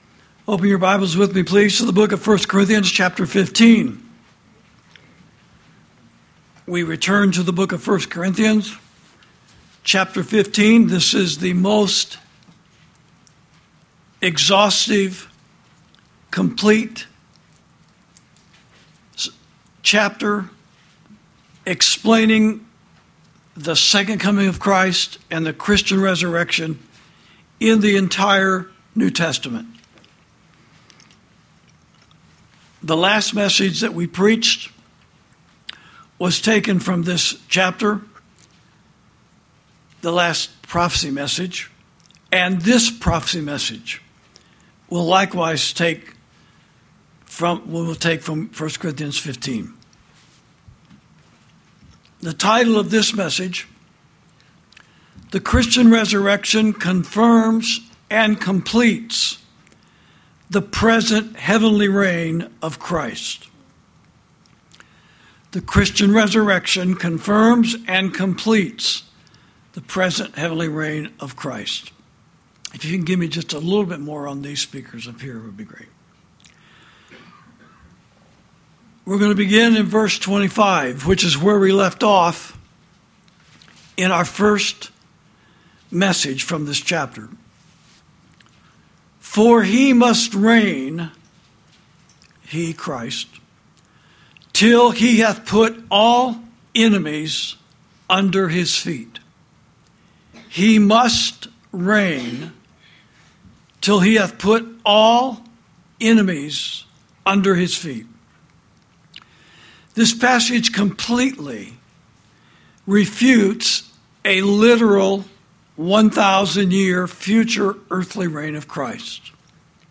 Sermons > The Christian Resurrection Confirms And Completes The Present Heavenly Reign Of Christ (Supplemental Prophecy Message Number Ten—Part One)